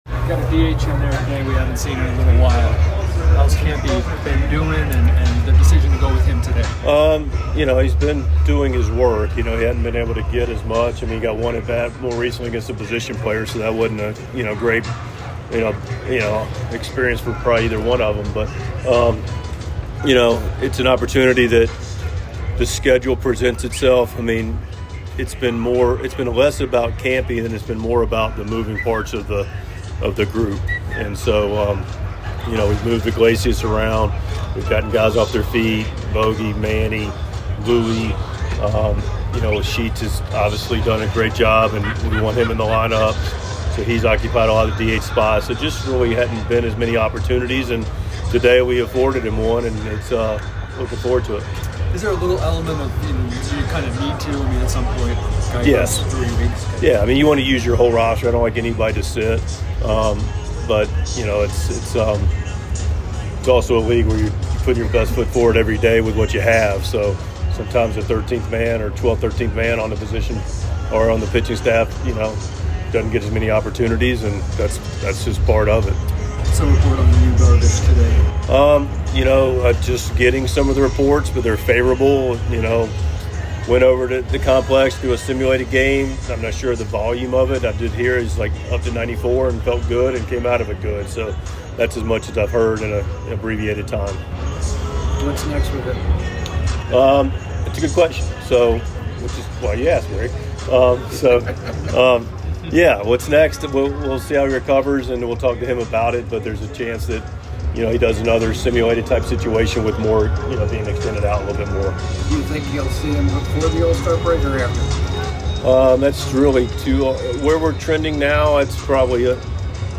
6.14.25 Mike Shildt Pregame Press Conference
Padres manager Mike Shildt speaks with the media before the team's game against the Arizona Diamondbacks on Saturday -- including thoughts about Luis Campusano getting a start at designated hitter. Shildt also provides the latest updates on Yu Darvish, Bryan Hoeing, and Jason Heyward.